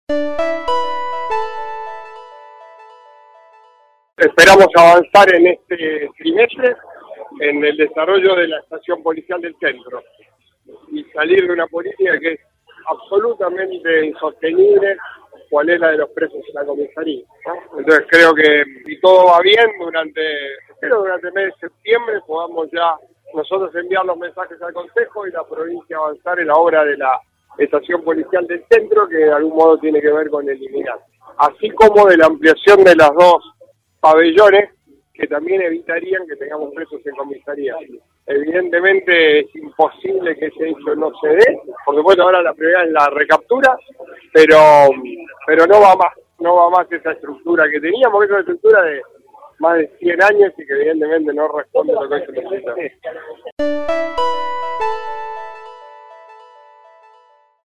En el marco de una actividad organizada por la provincia en La Fluvial, el intendente Pablo Javkin fue consultado sobre una nueva fuga de presos de la seccional 5ta. de Italia al 2100.